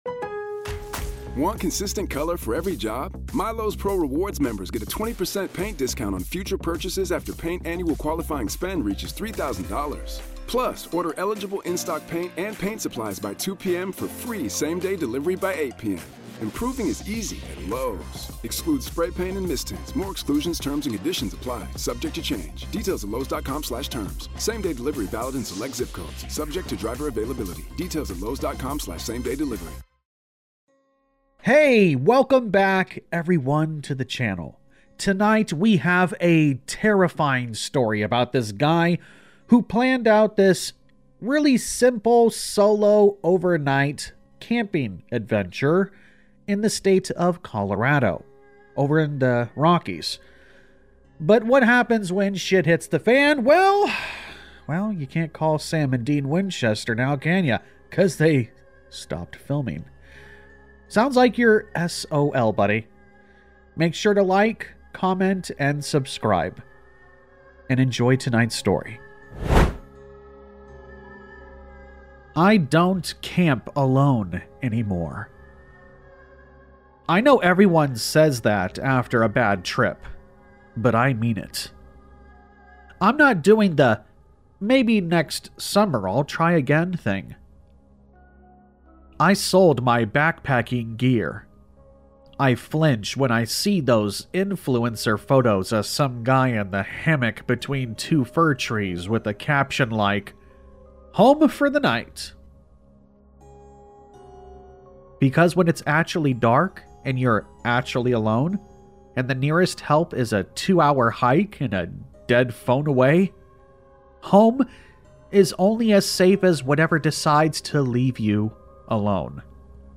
This gripping blend of True Scary Stories, Horror Stories, and Scary Stories will pull you into a suspense-filled mystery hidden in one of America’s most beautiful—and most dangerous—wilderness areas.
All Stories are read with full permission from the authors: